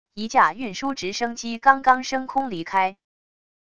一架运输直升机刚刚升空离开wav音频